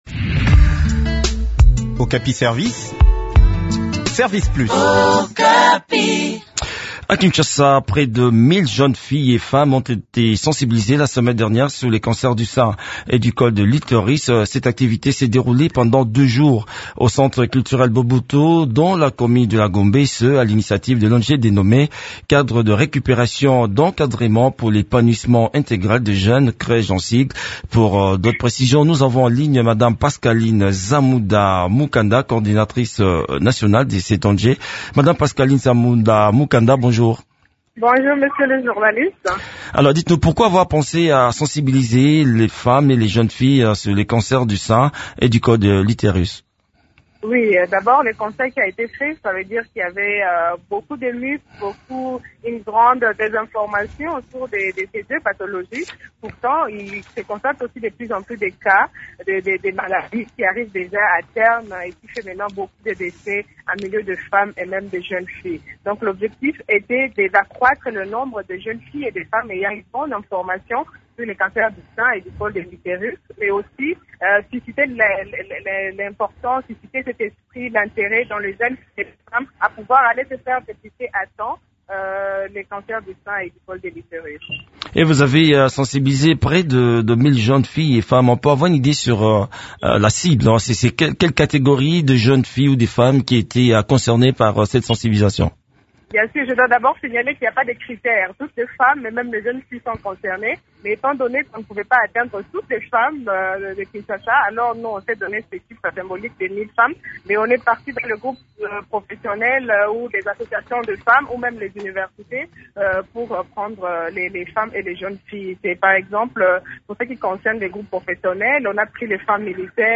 Le point sur le déroulement de cette activité dans cet entretien